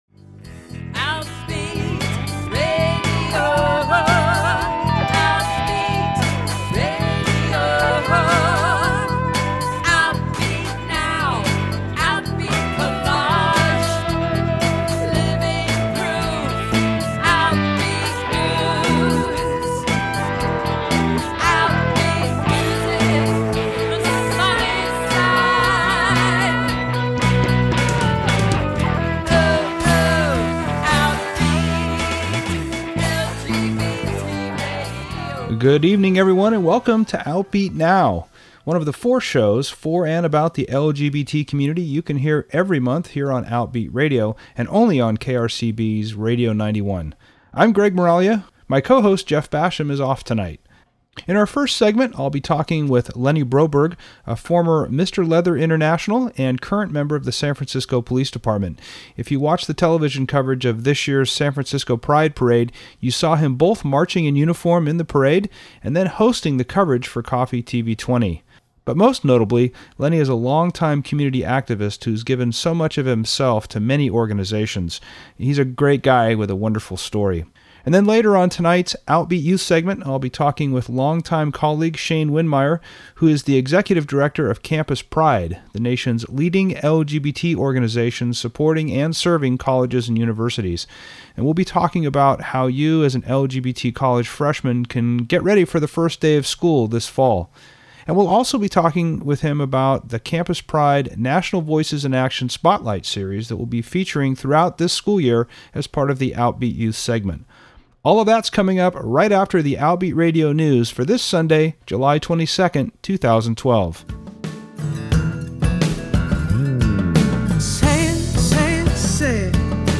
Outbeat Radio is a weekly radio program for and about the lesbian, gay, bisexual and transgender community in the California North Bay airing on KRCB Radio 91.1 FM